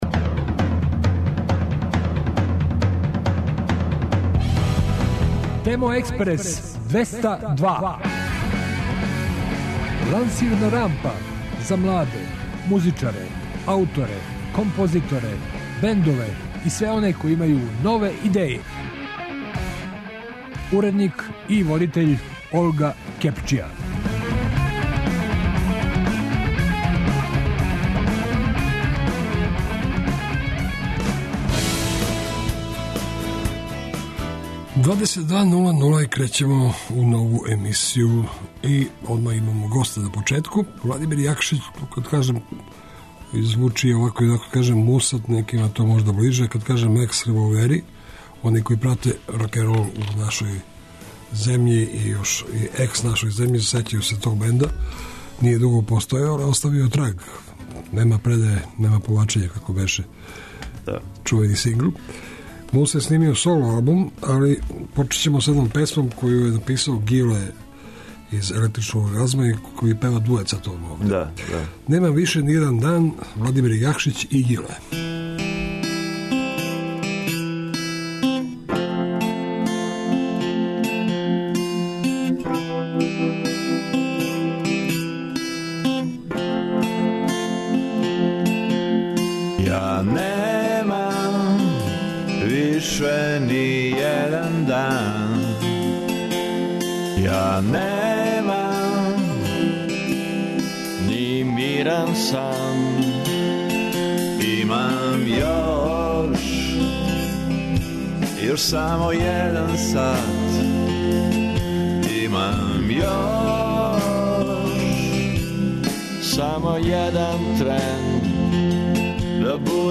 И у овонедељној емисији вас очекуjу сјајни гости, много нове музике, интересантни нови бендови и издања.